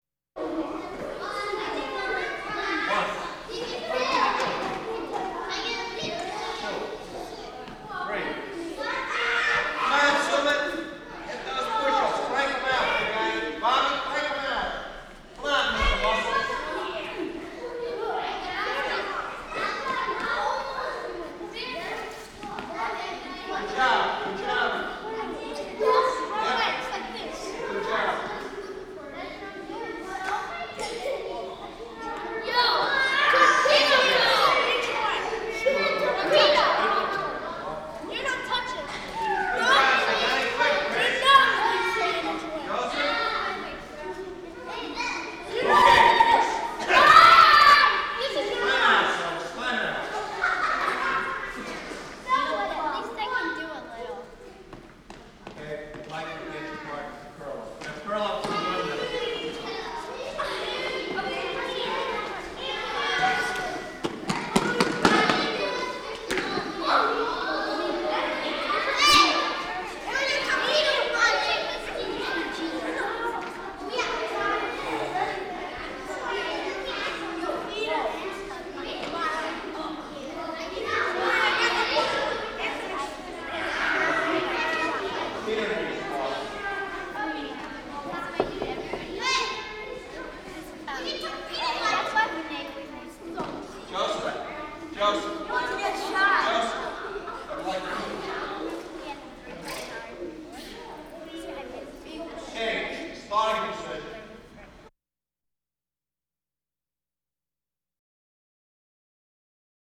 ambience
School Crowd - Children At Gym Class